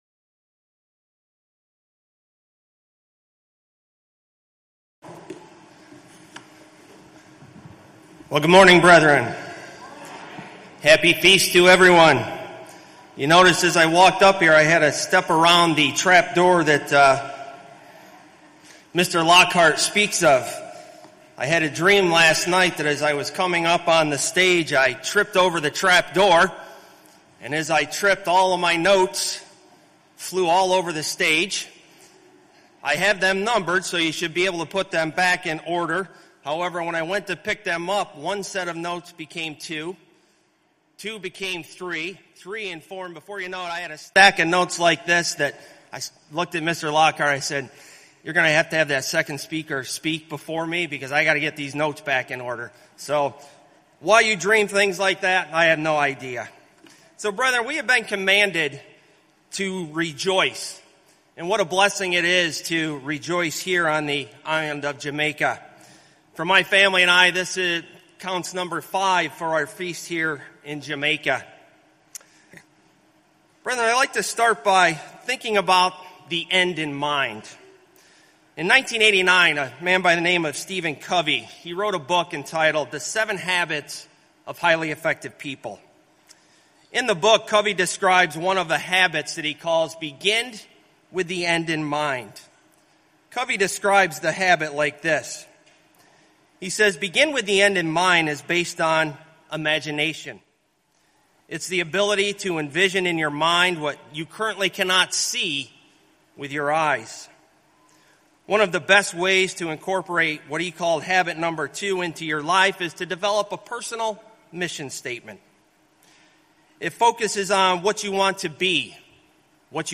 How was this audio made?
This sermon was given at the Montego Bay, Jamaica 2022 Feast site.